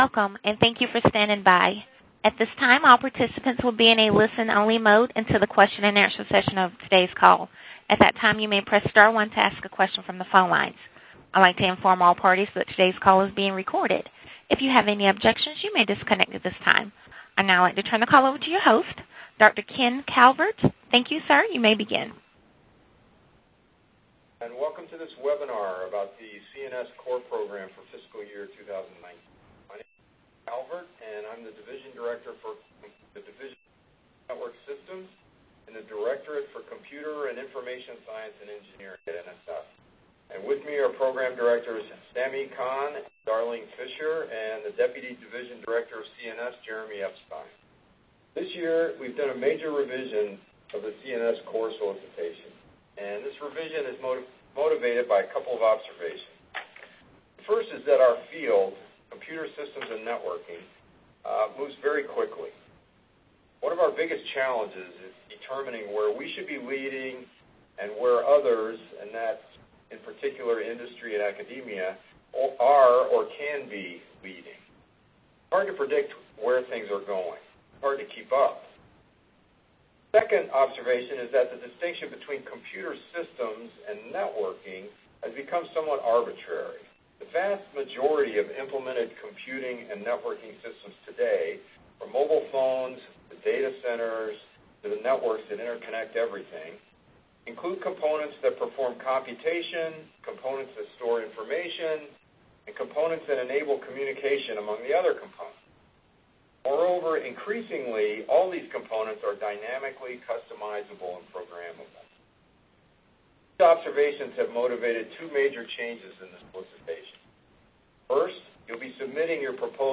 Computer and Network Systems (CNS) Core Program Solicitation Webinar